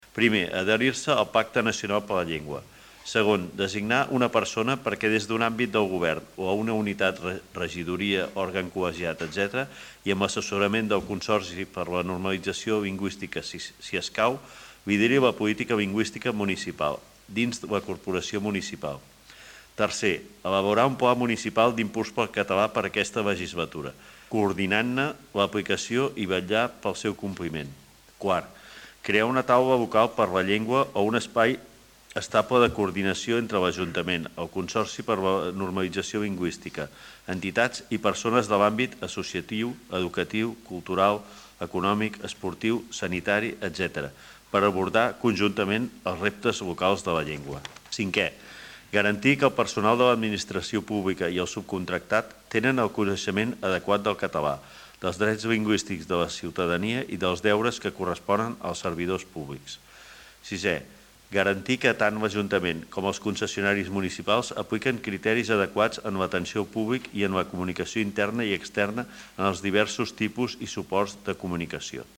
Lluís Sagarra, regidor de Seguretat Ciutadana i Mobilitat